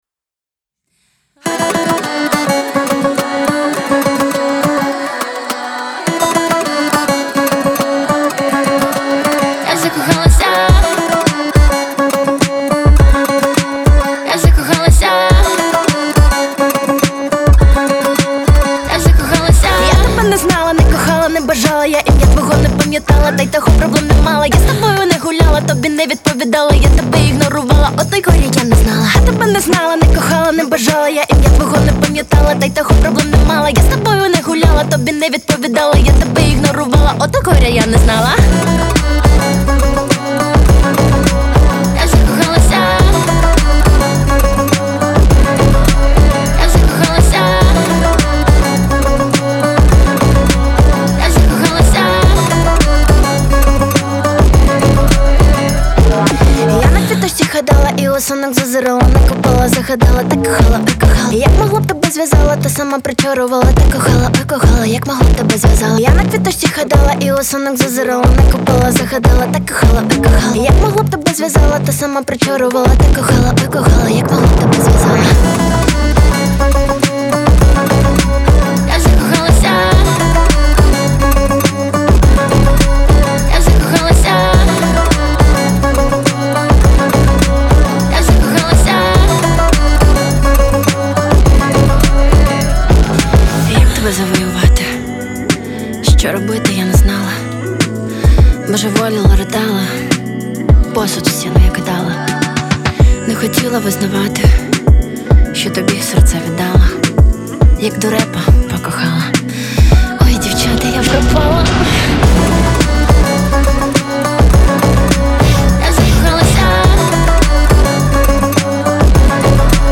• Жанр: Pop, Rap